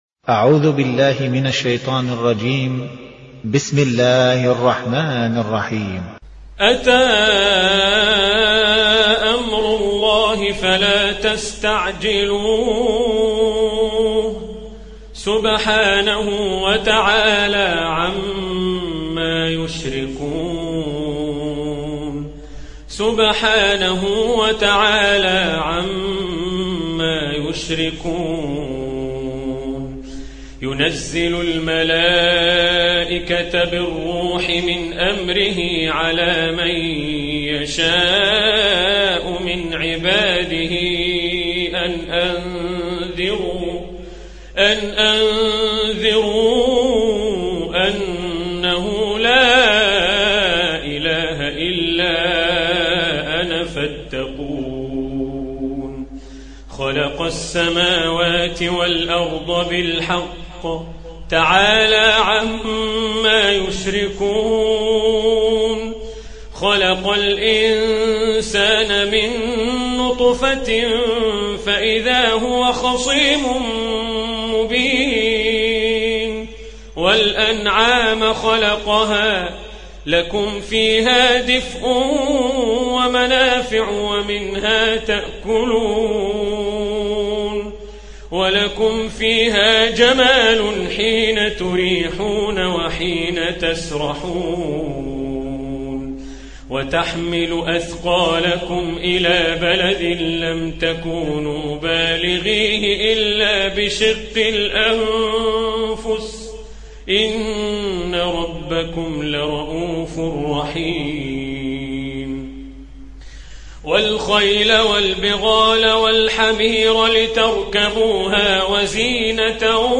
Hafs an Assim
Moratal